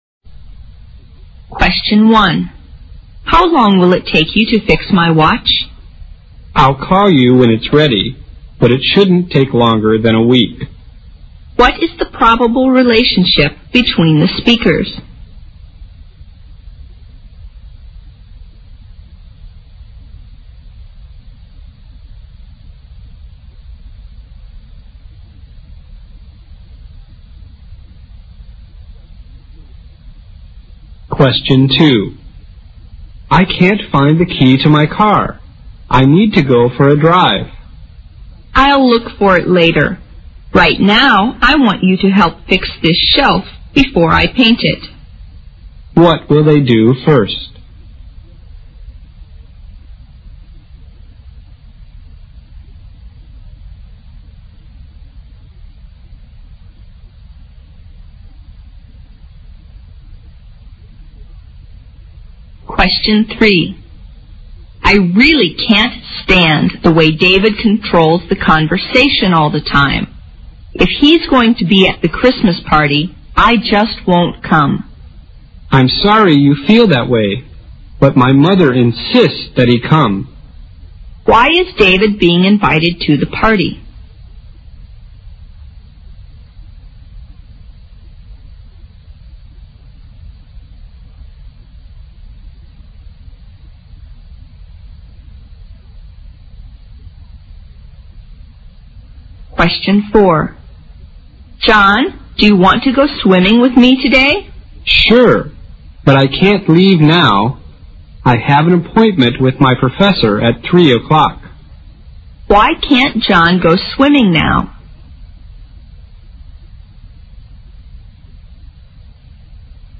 【CET12-24备战】四级听力 第十三期 Short Conversation 听力文件下载—在线英语听力室